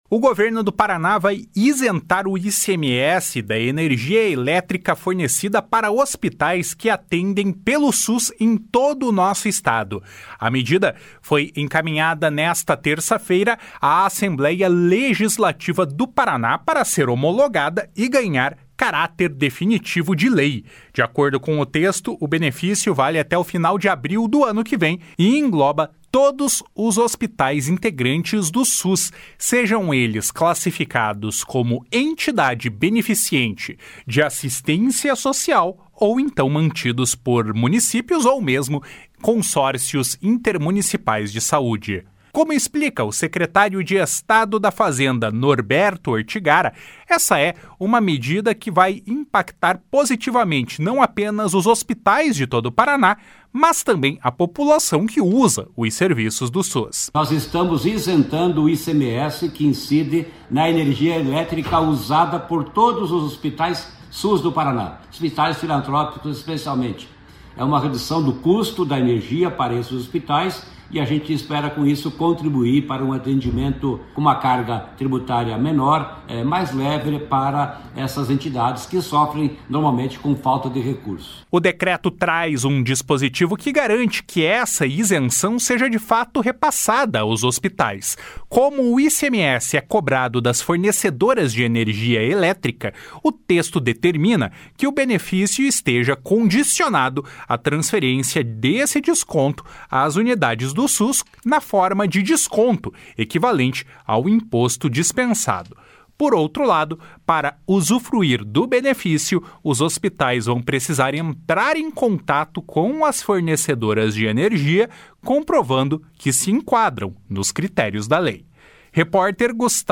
// SONORA NORBERTO ORTIGARA //